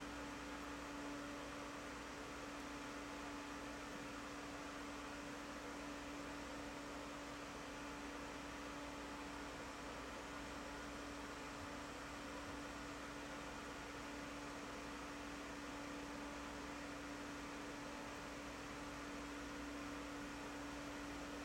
All clips were recorded under the same conditions, using an iPhone 16 Pro placed 3 feet away from the fan, with the fan running at full speed and blowing away from the microphone.